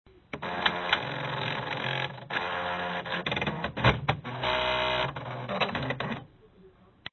Brother PX50 Startup.wav